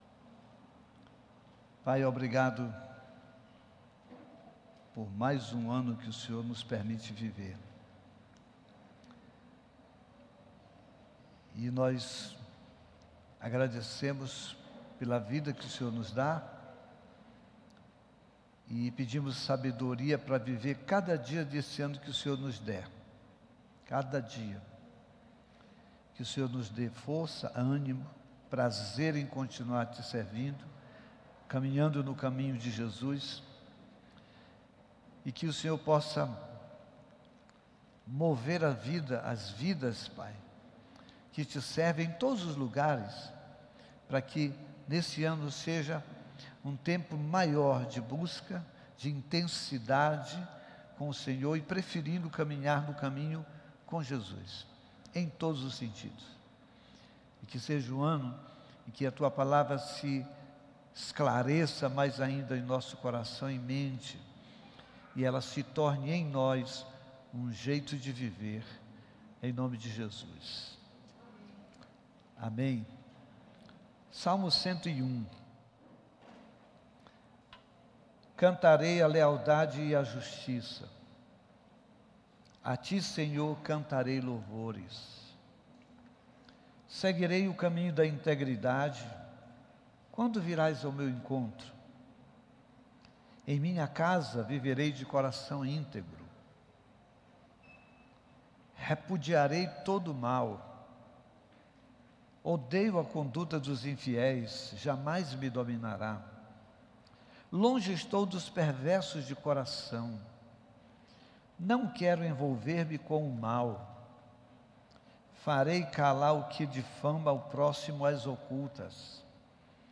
Culto presencial aos domingos às 18 h.